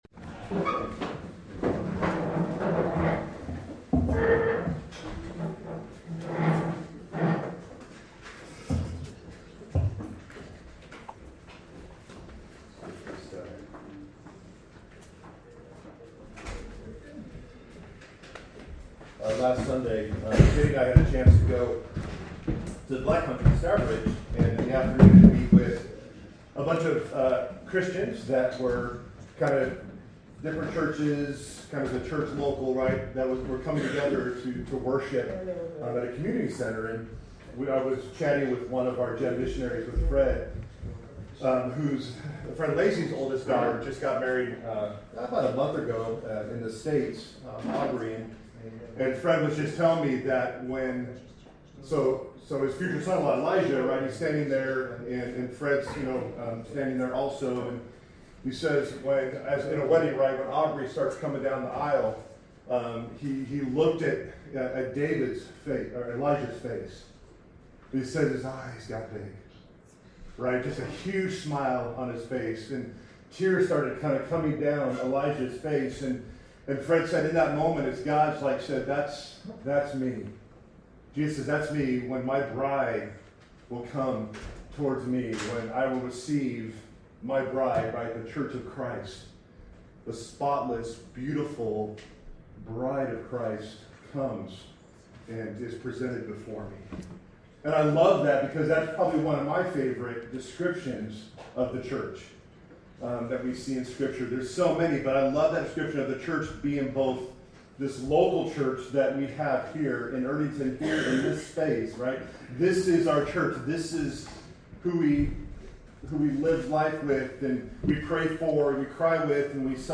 Sermons by OIKOS Church